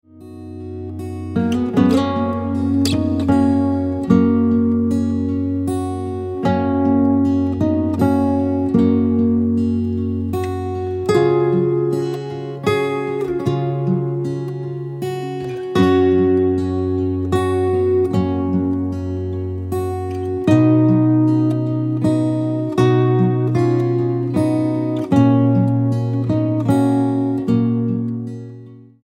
STYLE: Pop
instrumental renditions of carols
a pleasant and relaxing collection